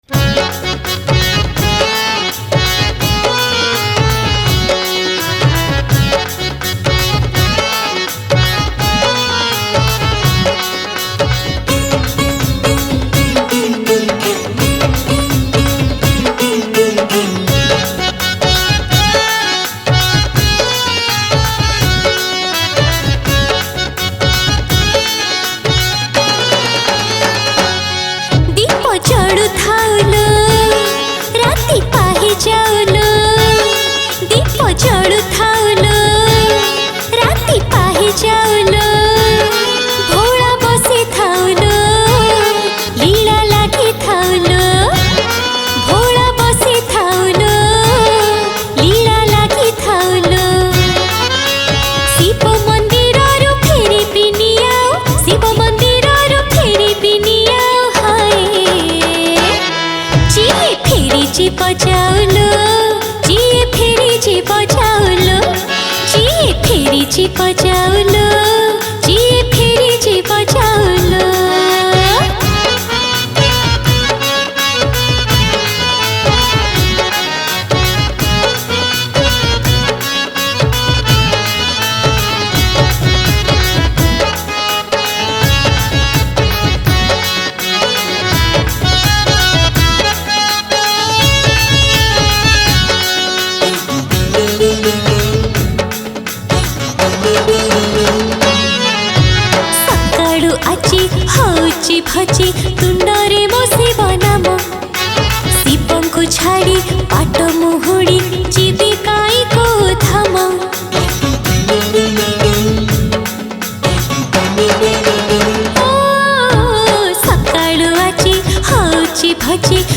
Jagara Special Odia Bhajan Song 2022 Songs Download